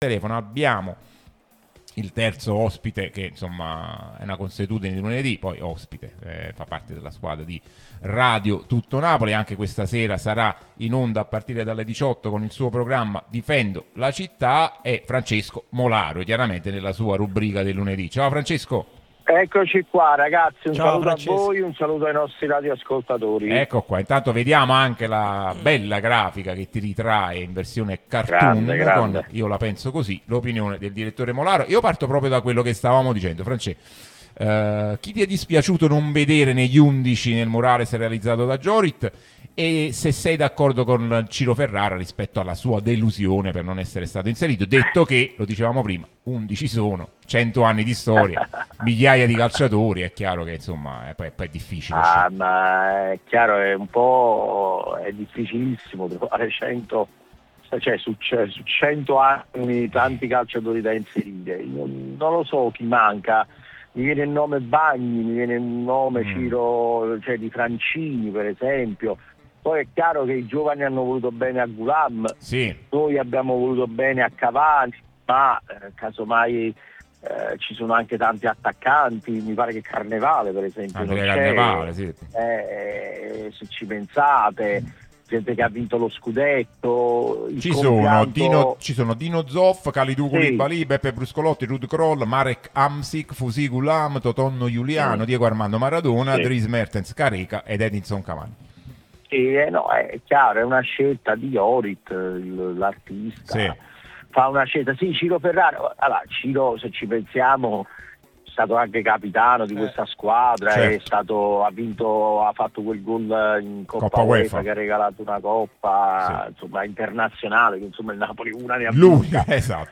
prima radio tematica sul Napoli, in onda tutto il giorno